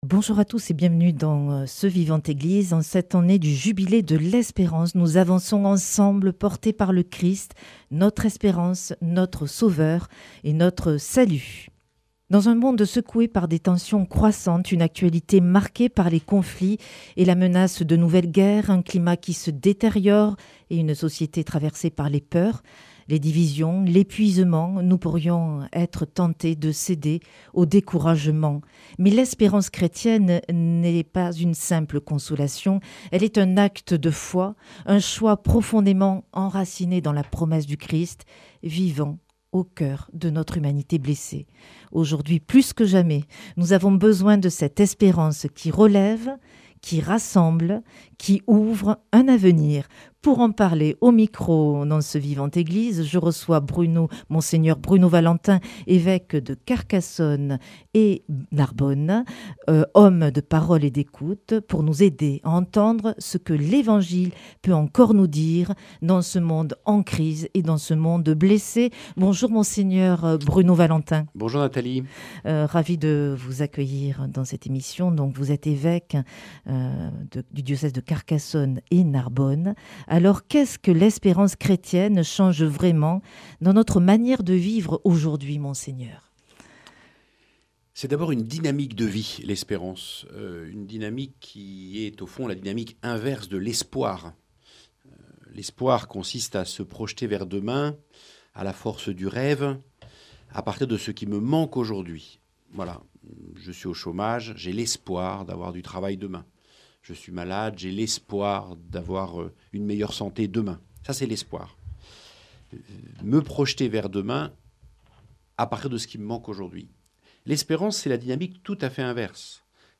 En cette année du Jubilé de l'Espérance, Vivante Église reçoit Mgr Bruno Valentin, évêque de Carcassonne et Narbonne.